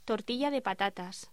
Locución: Tortilla de patatas
voz